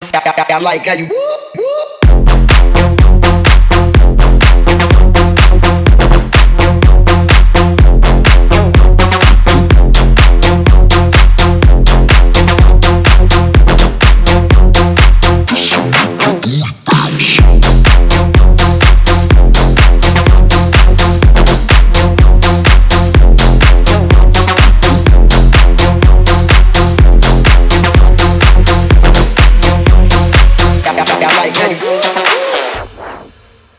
• Качество: 320, Stereo
громкие
мощные
мощные басы
Bass House
electro house
Melbourne Bounce
клубная музыка
танцевальная музыка
Жанр: Танцевальная/электронная музыка